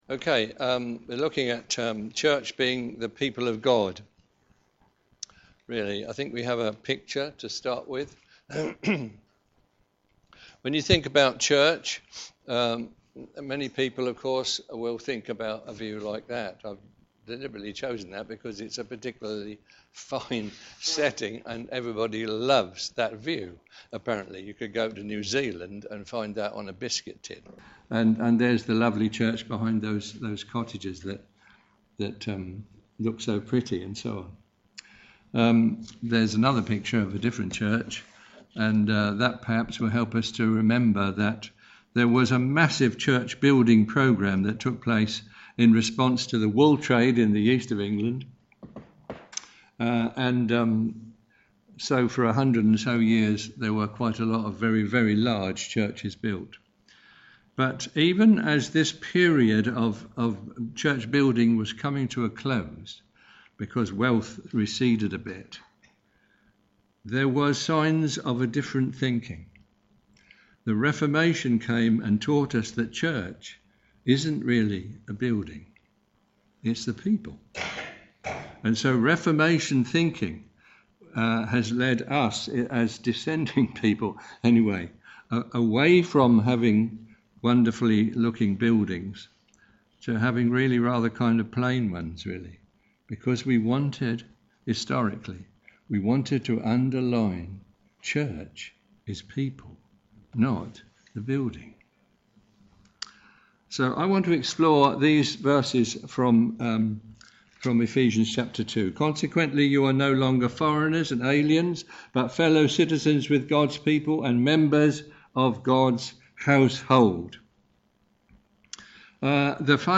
a.m. Service
Theme: Gods Building Sermon